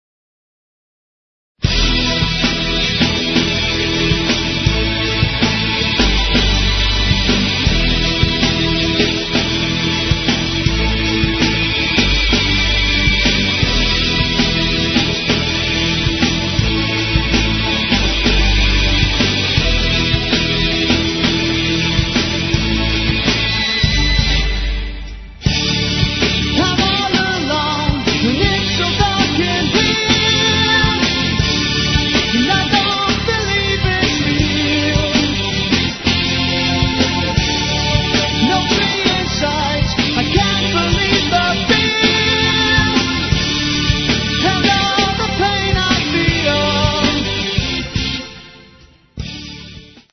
Farm Studio, Rainham, Essex.